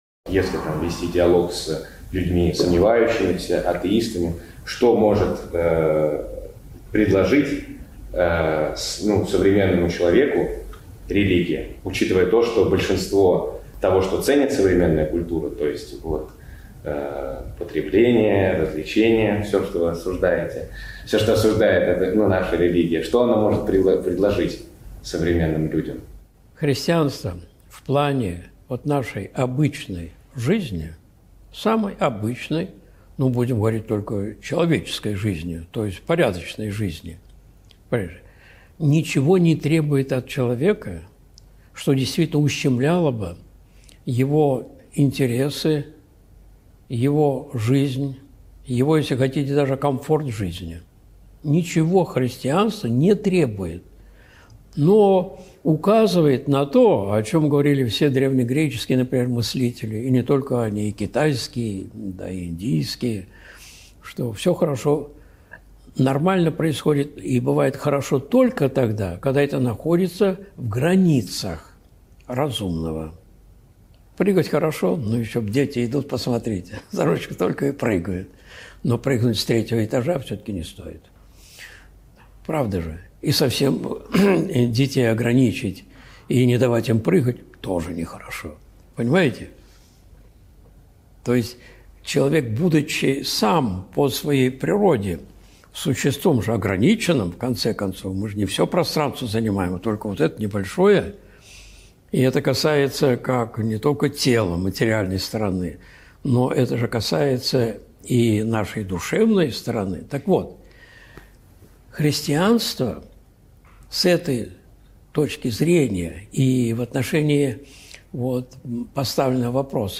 Нас отучают думать! (Встреча с молодёжью, 04.07.2022)
Видеолекции протоиерея Алексея Осипова